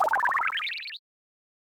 Cri de Flotillon dans Pokémon Écarlate et Violet.